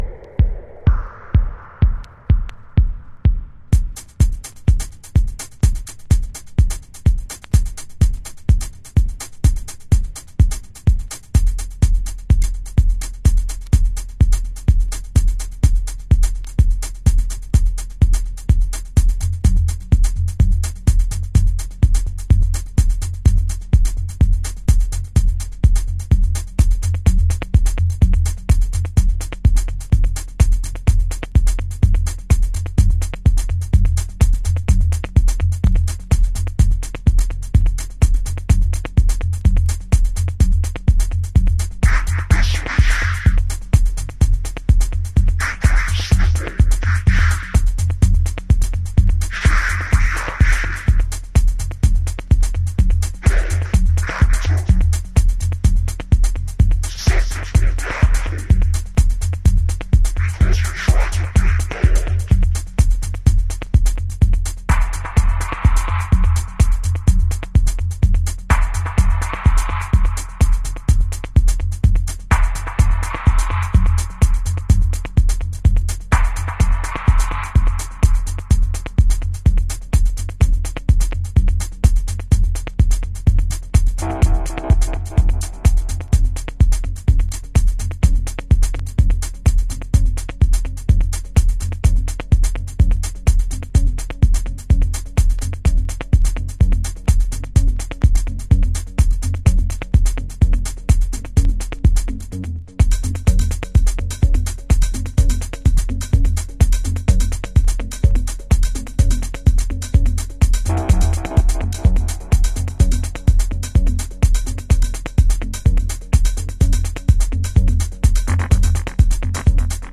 House / Techno
重心低めのドープなグルーヴにピッチを下げたボコーダーボイス控えめアシッドがバッドトリップを誘発！